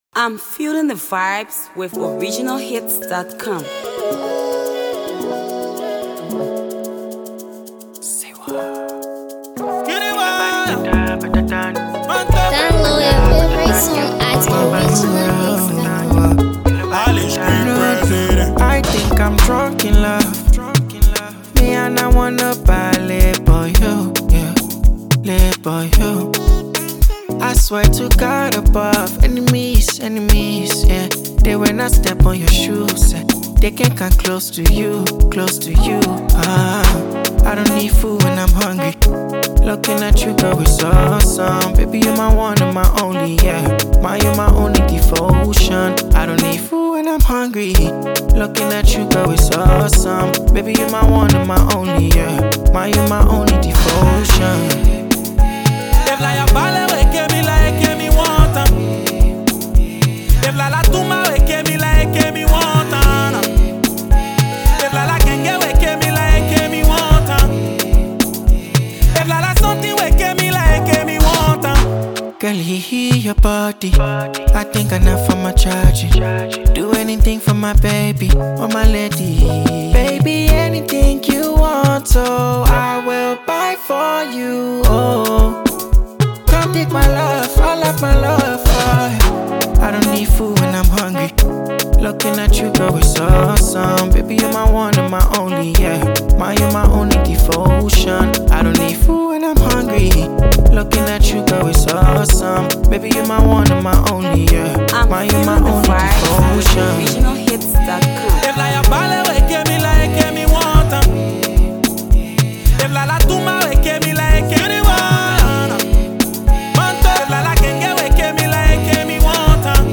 Afro Fresh vibing tune
chilling banger